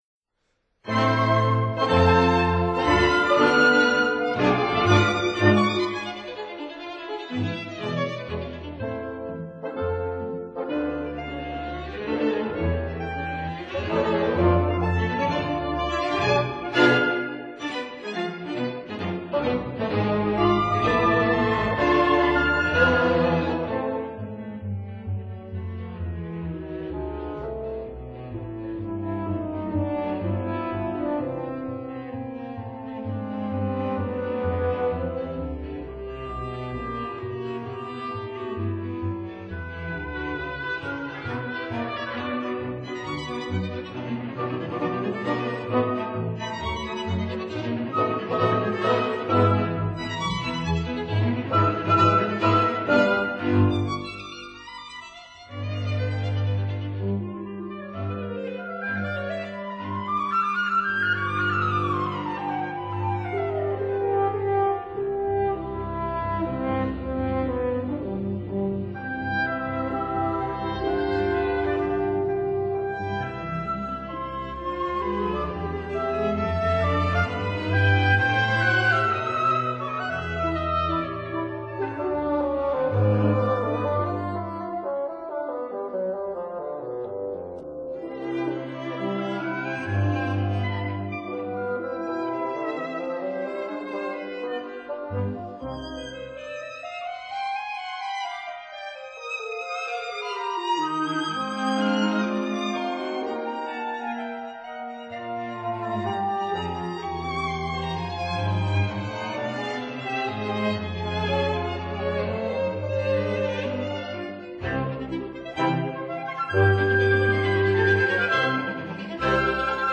flute
clarinet
horn
violin
viola
cello